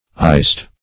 Iced \Iced\ ([imac]st), a.